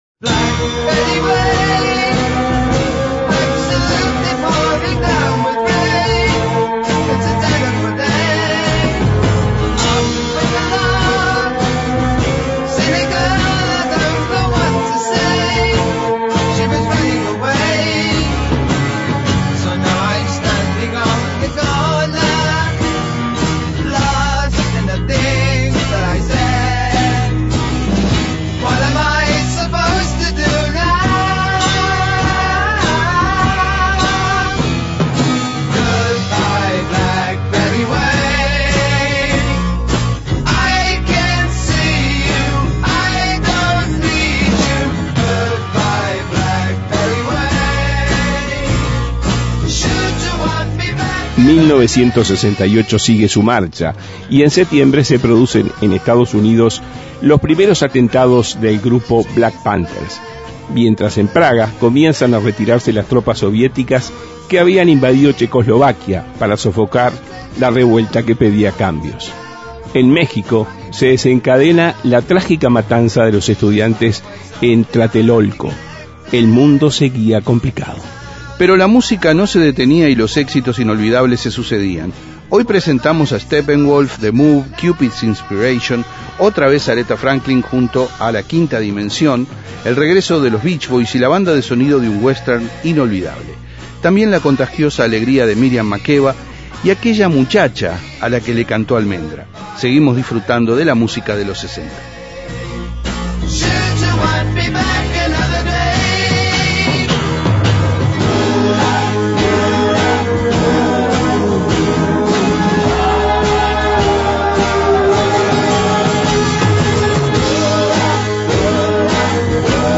Seguimos disfrutando de la música de los 60.